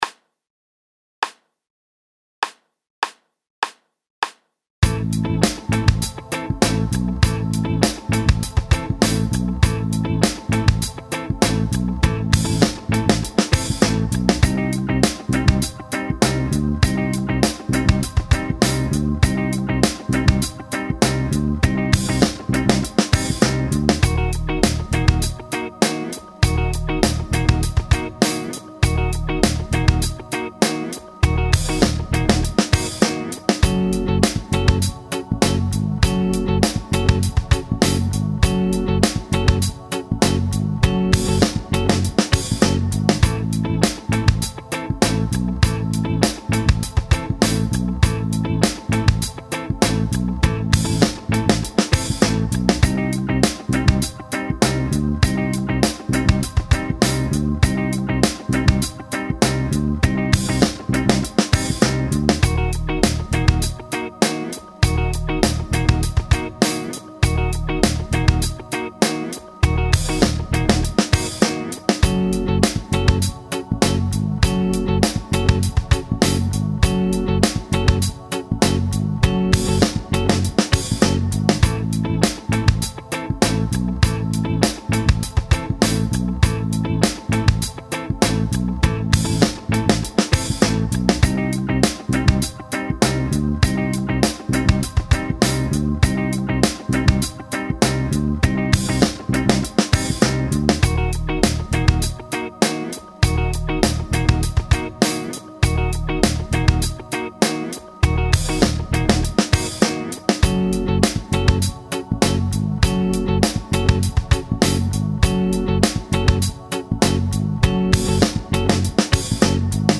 Style Funk groove Tempo 100BPM Keys Am – Cm – Ebm – F#m